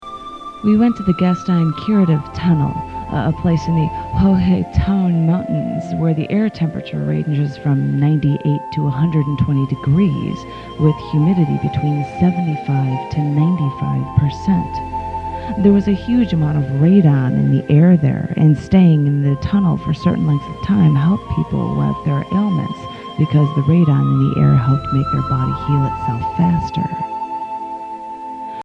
real audio studio SAMPLE